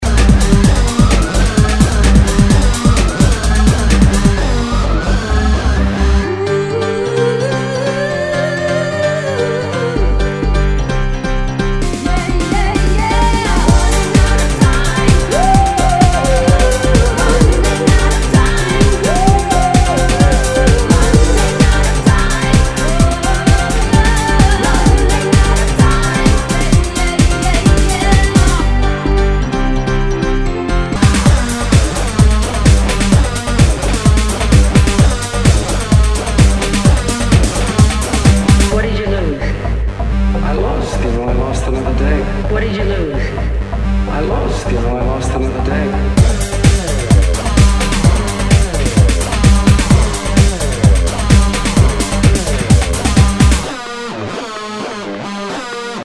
HOUSE/TECHNO/ELECTRO
ユーロ・ハウス / テクノ・クラシック！！